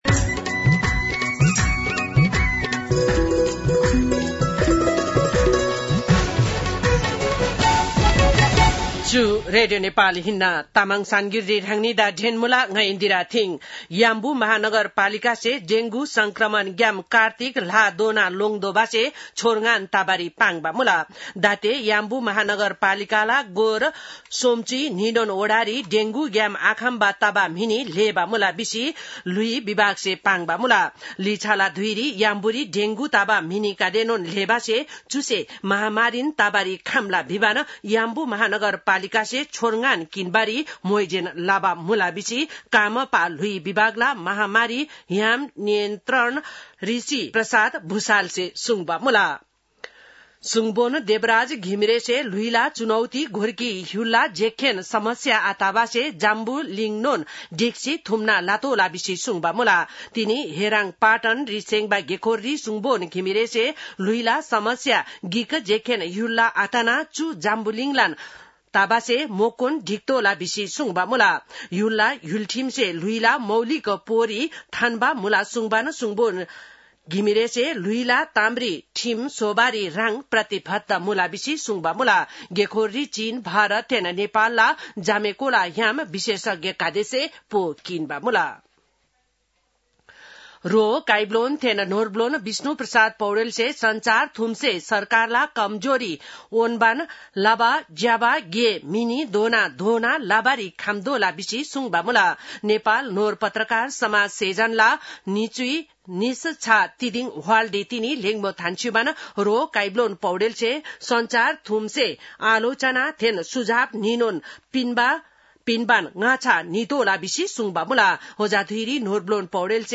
तामाङ भाषाको समाचार : २१ भदौ , २०८२